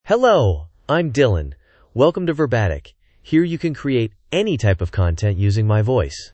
DylanMale English AI voice
Dylan is a male AI voice for English (United States).
Voice sample
Male
Dylan delivers clear pronunciation with authentic United States English intonation, making your content sound professionally produced.